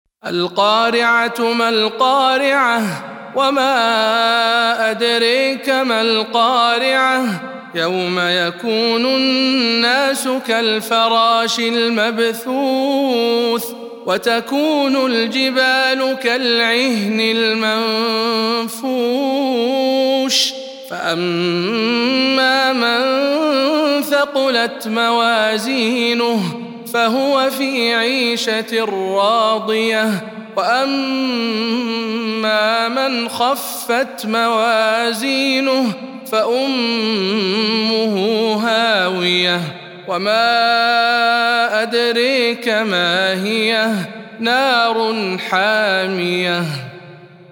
سورة القارعة - رواية خلاد عن حمزة